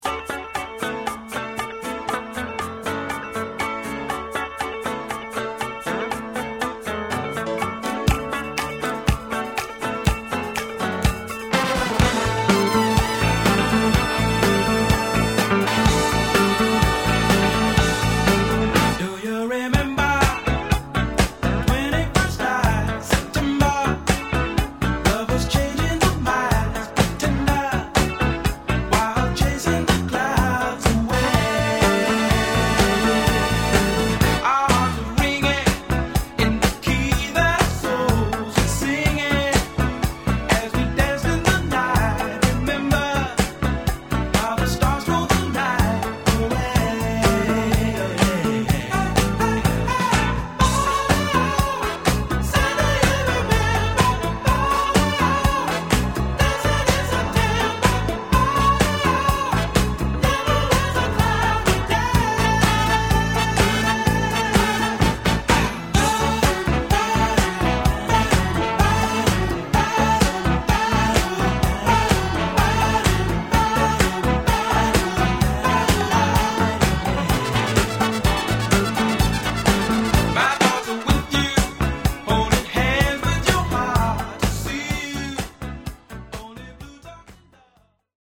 con una sezione di fiati